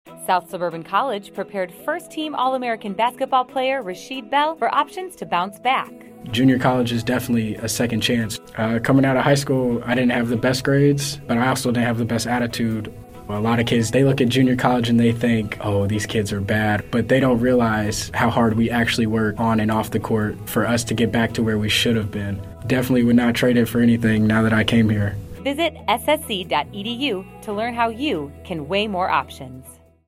Audio Spots
Radio spot utilizing student testimonial mixed with a voice-over talent promoting South Suburban College.